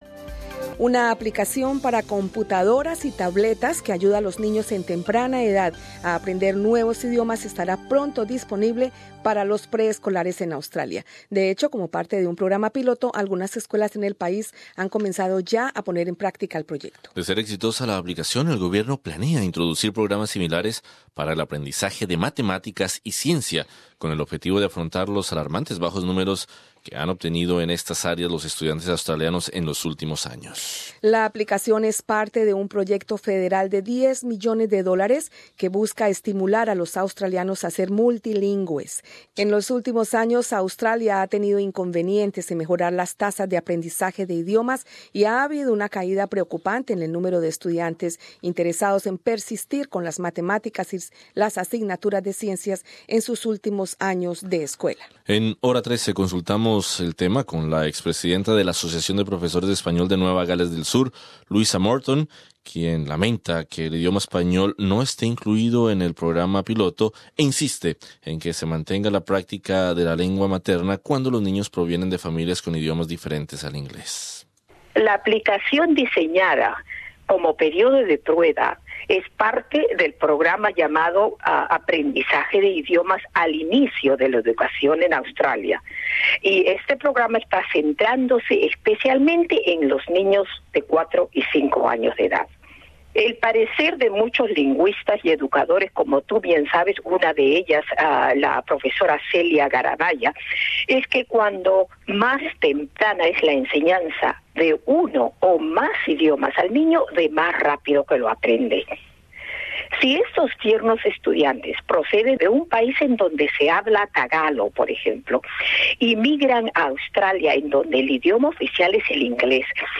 Una aplicación para computadoras y tabletas que ayuda a los niños entre 4 y 5 años a aprender nuevos idiomas, estará pronto disponible para los preescolares en Australia. La aplicación no incluye aún el español. Entrevista